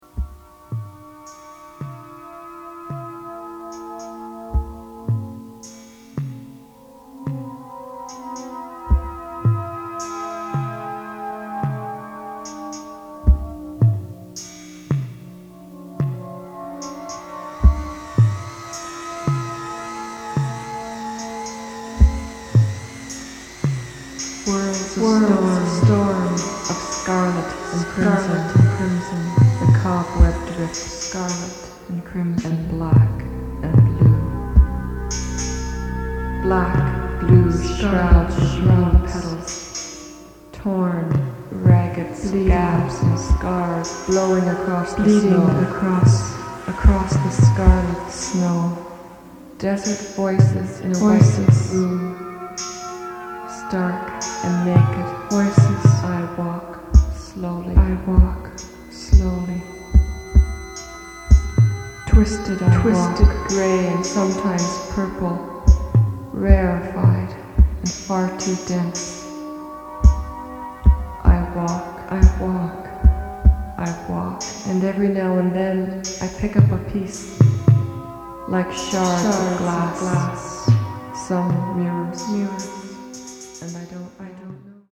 取り留めもなく夢の風景を辿る、夢日記仕立てのポエトリーシンセポップ。
キーワード：宅録　乙女　ミニマル